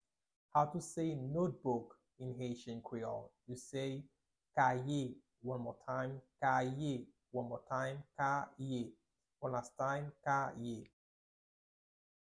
Pronunciation:
19.How-to-say-Notebook-in-haitian-creole-–-Kaye-pronunciation.mp3